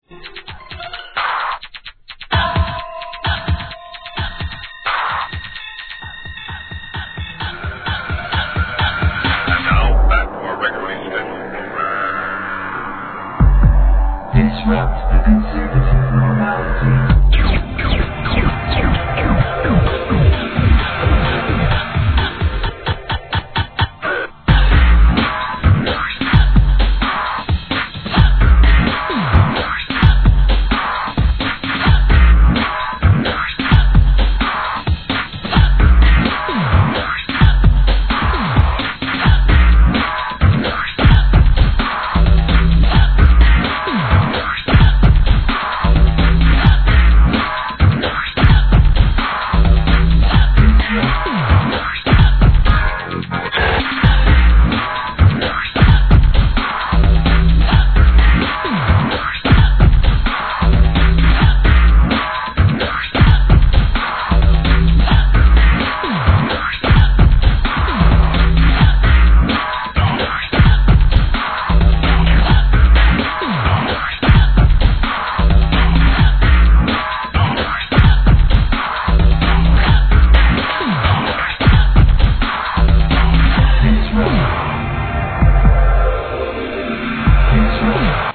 HIP HOP/R&B
ブレイク・ビーツ No. タイトル アーティスト 試聴 1.